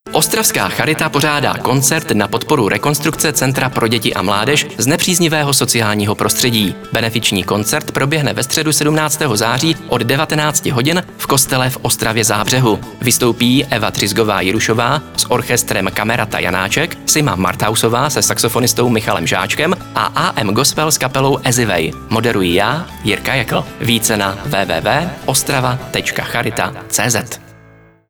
Rádiový spot koncertu (Hitrádio Orion)